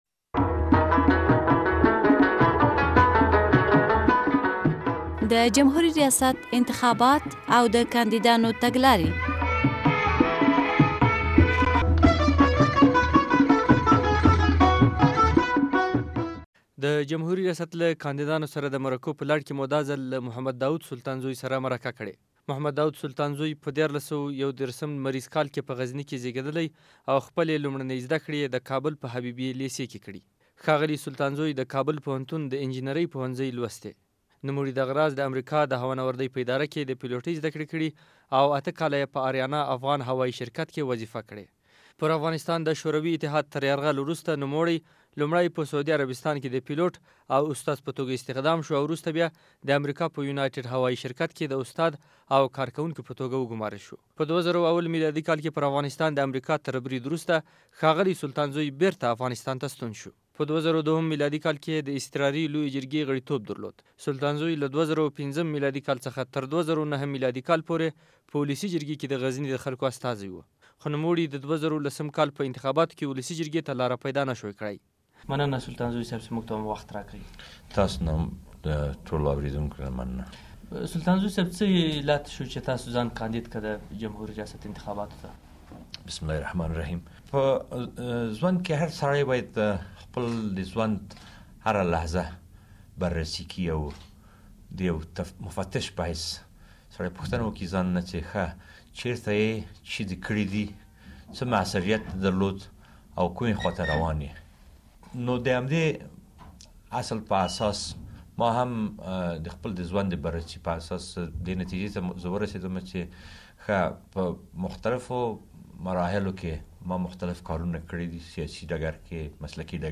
له داود سلطانزوى سره مرکه